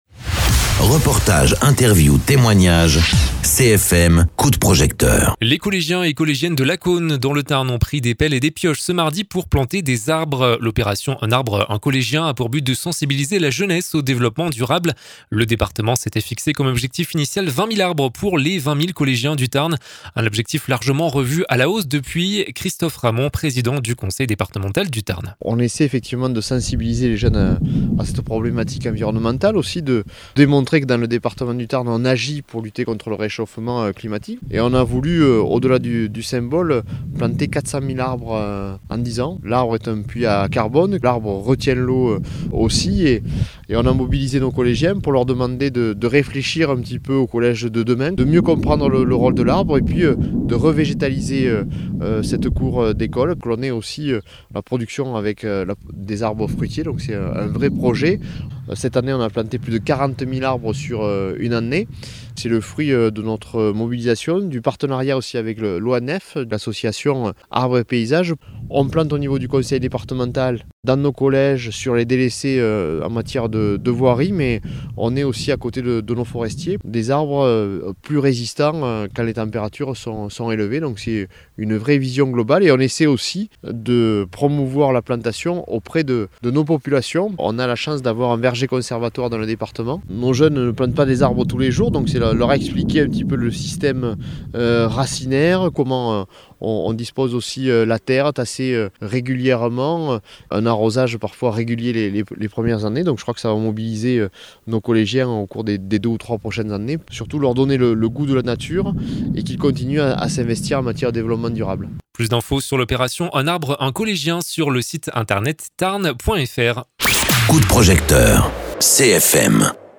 Interviews
Invité(s) : Christophe Ramond, président du conseil départemental du Tarn